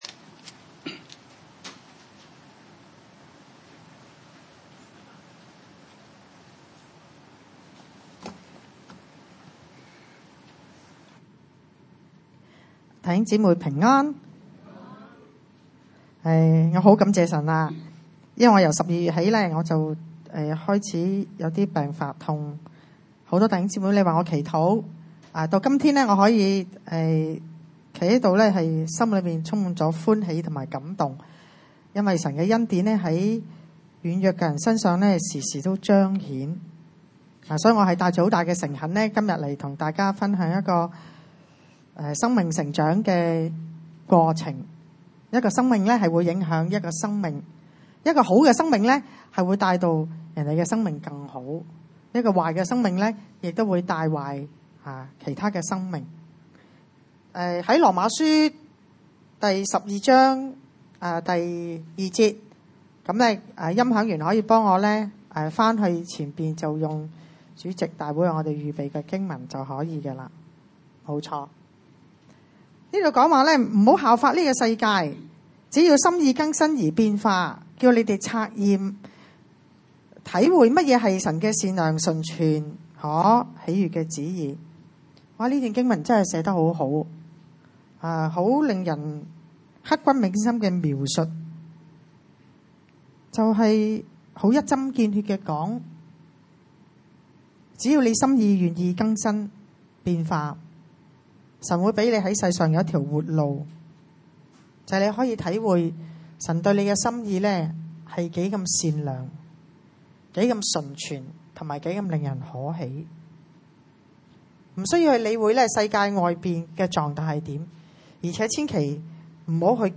羅馬書12:2、提摩太後書1:3-12 崇拜類別: 主日午堂崇拜 羅馬書 第 12 章 2 節 不要效法這個世界，只要心意更新而變化，叫你們察驗何為 神的善良、純全、可喜悅的旨意。